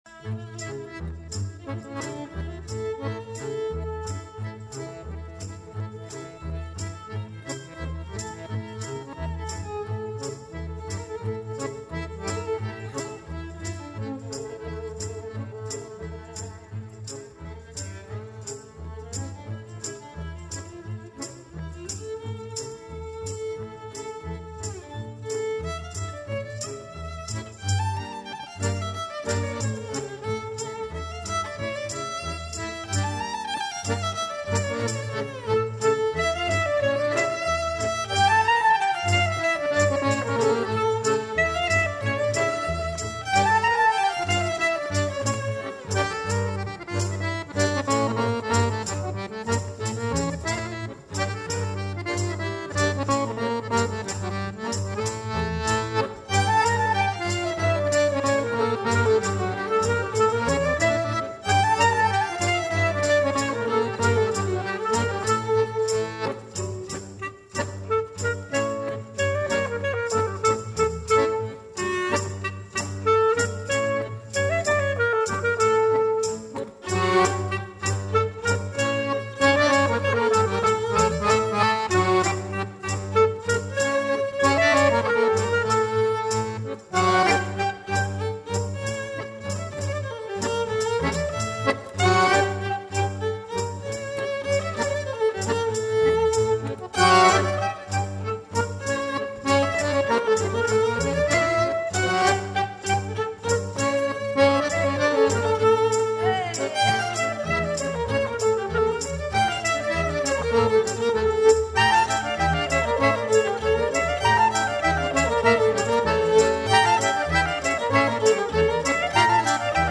KLezmermusik und jiddische Lieder